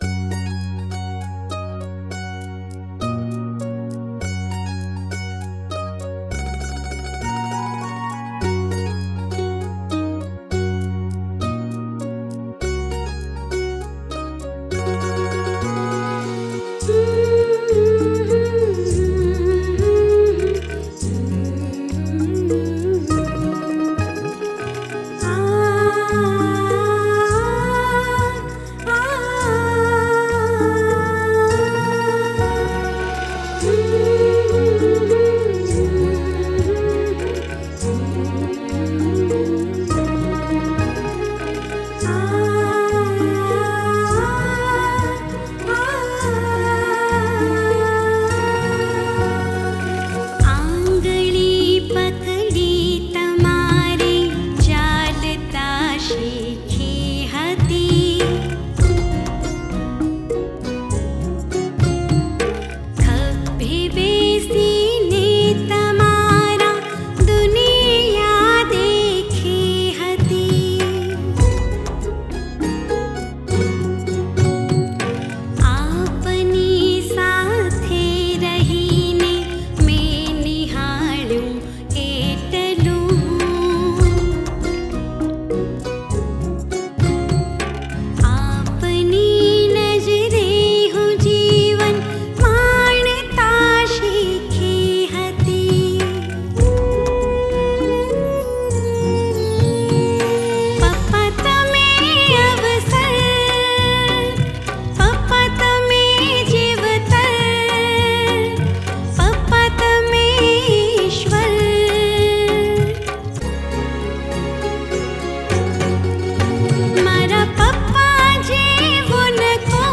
Category: GUJARATI NON STOP GARBA SONG